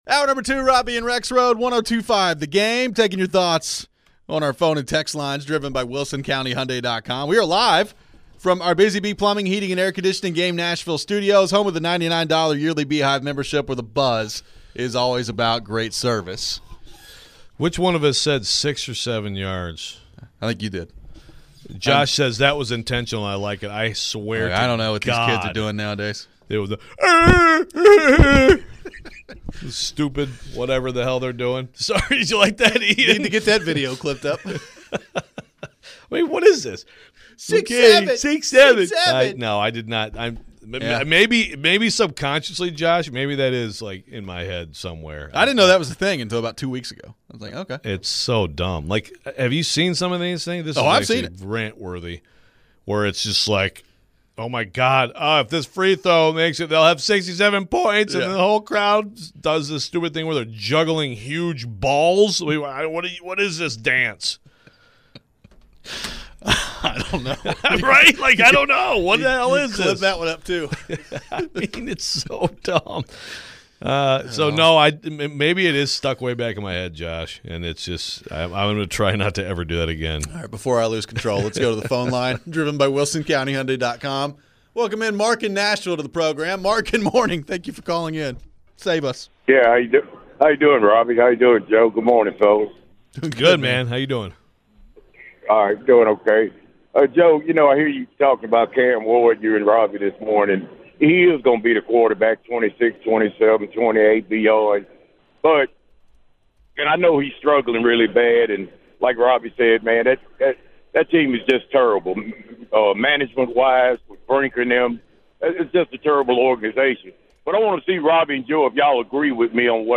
We begin the 2nd hour with more reaction to the Titans and take your phones. How much faith should we have in the front office for the team to get the draft right?